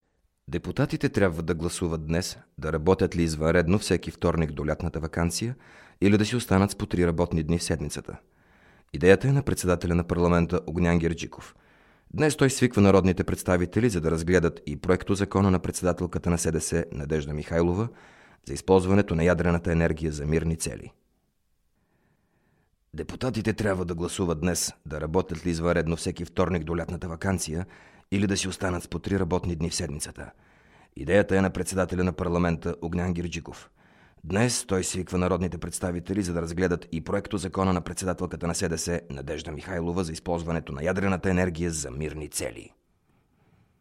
Bulgarian – male – AK Studio